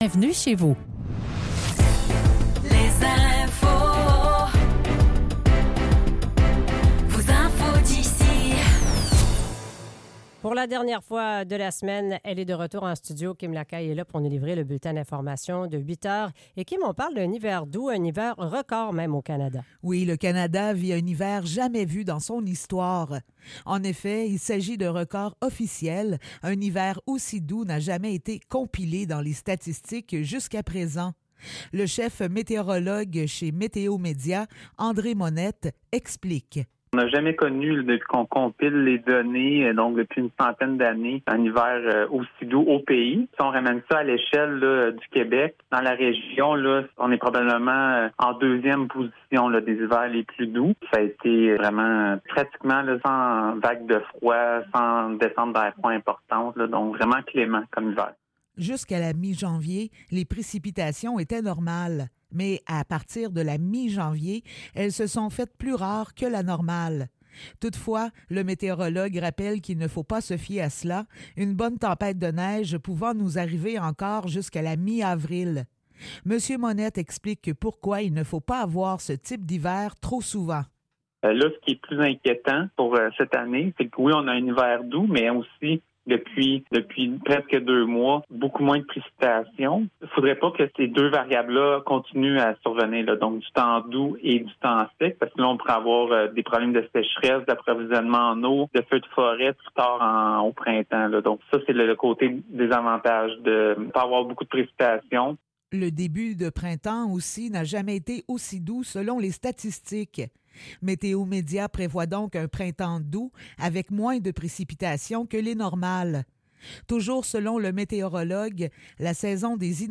Nouvelles locales - 22 mars 2024 - 8 h